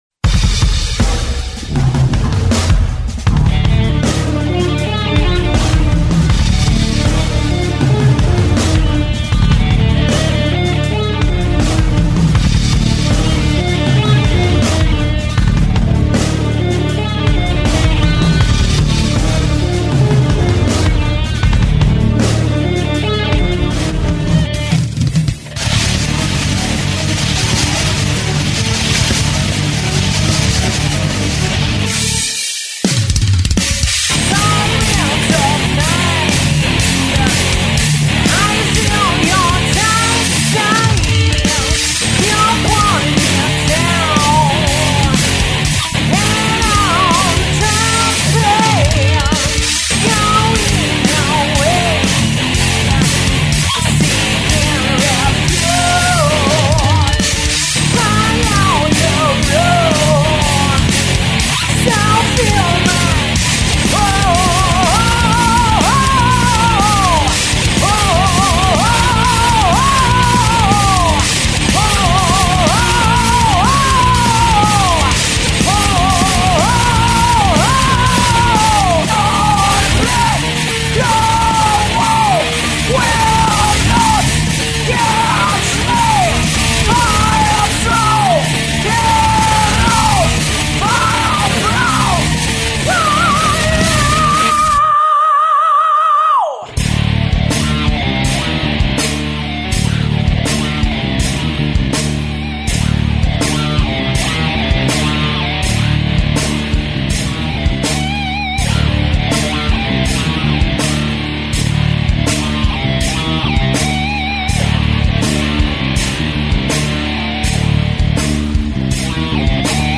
Bass
Execution Drums..